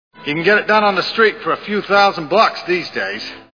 Minority Report Movie Sound Bites